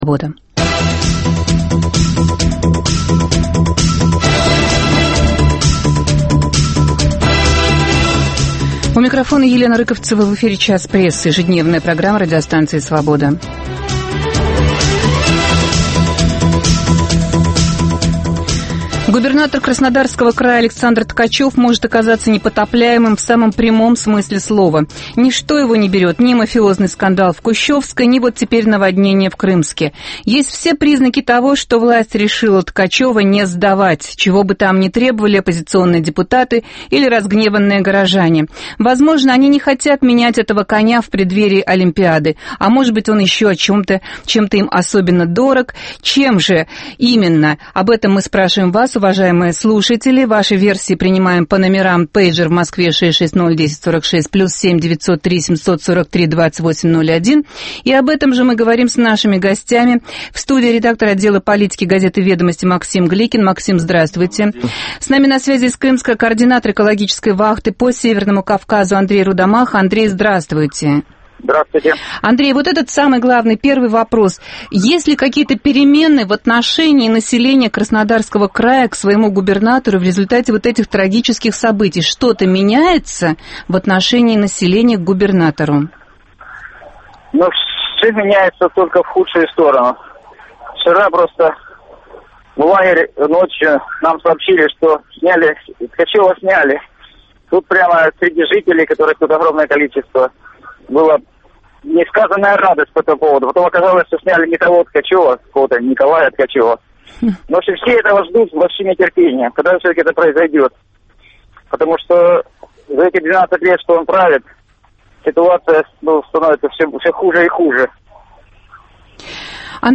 оба - по телефону из Крымска, особенно пострадавшего от наводнения